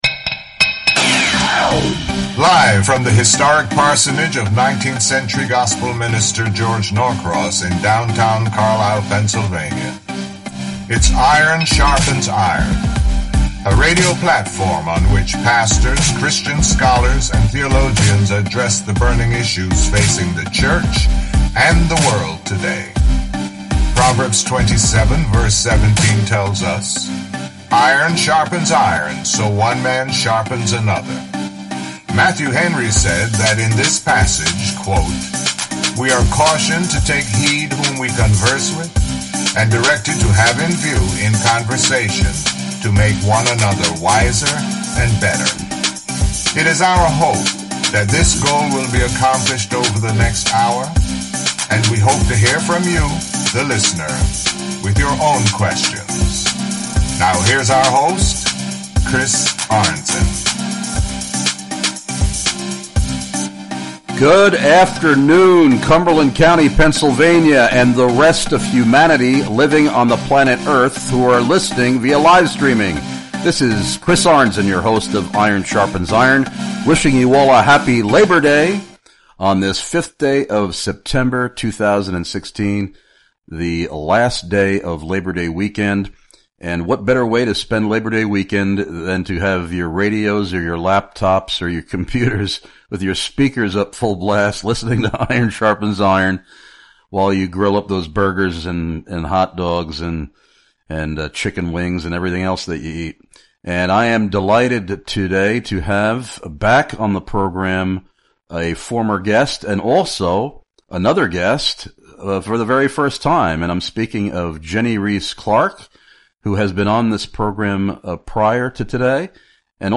IRON SHARPENS IRON Radio’s 2 guests for *TODAY*: